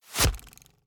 Bow Attacks Hits and Blocks
Bow Blocked 3.wav